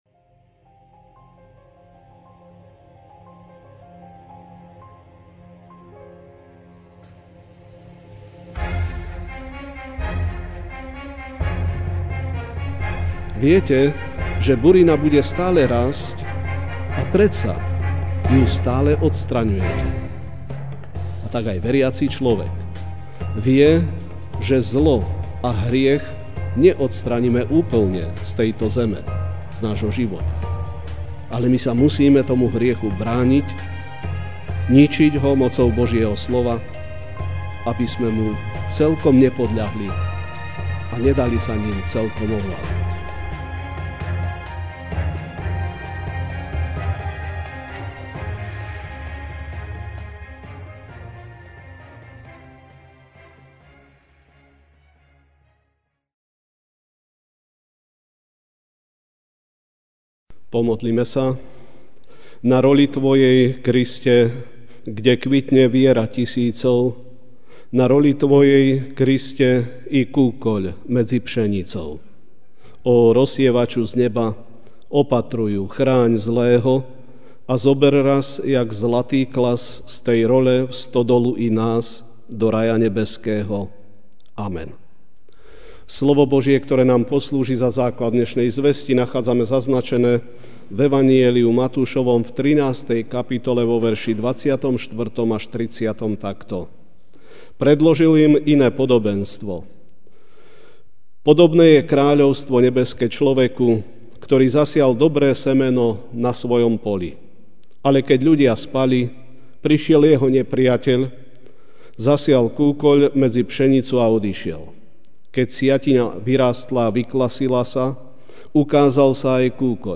Večerná kázeň: O kúkoli a pšenici (Mt. 13, 24-30) Predložil im iné podobenstvo: Podobné je kráľovstvo nebeské človeku, ktorý zasial dobré semeno na svojom poli.